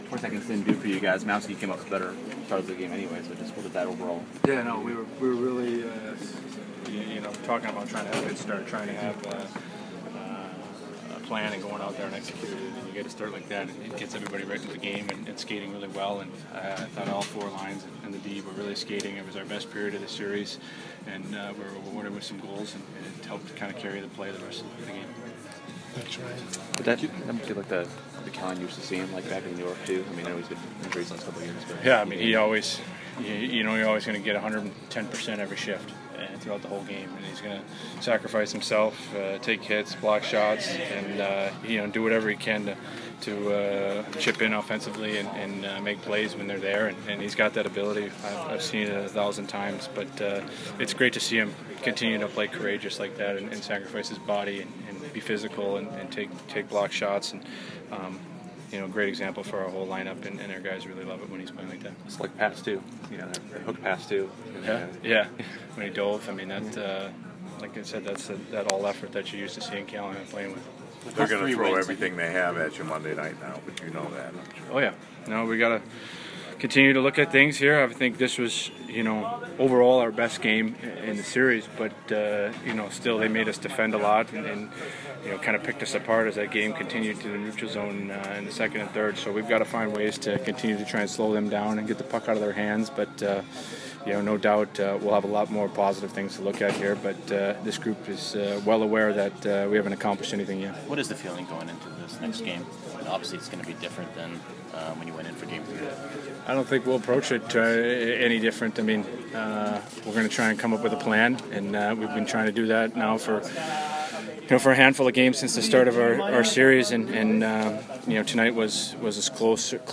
Ryan McDonagh post-game 5/19